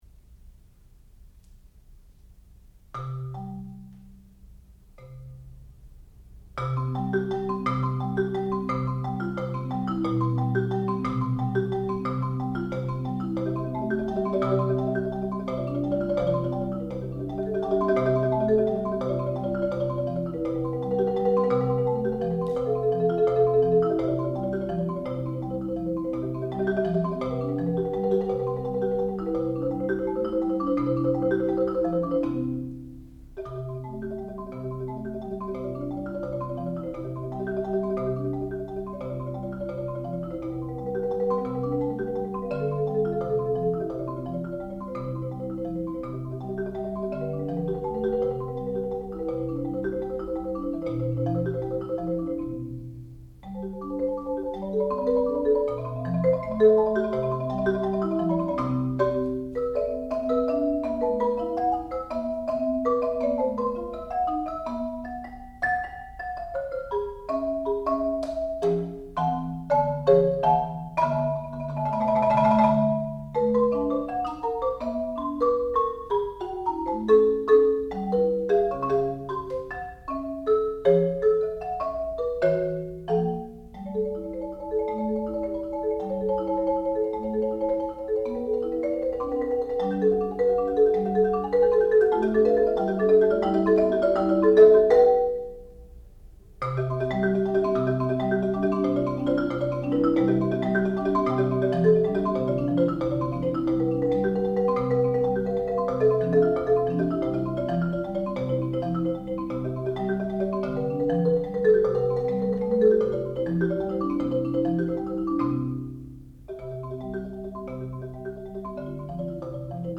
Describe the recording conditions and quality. Master Recital